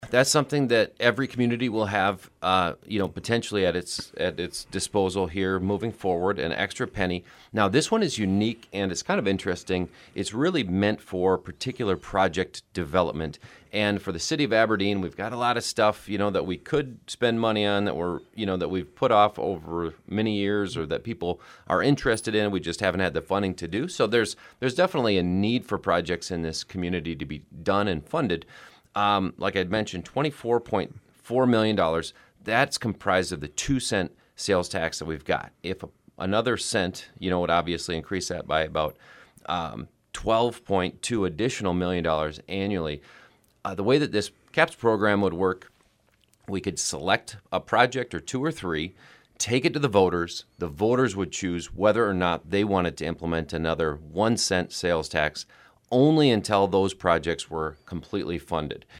On Wednesday he appeared on the KSDN Midday Report to talk more about that.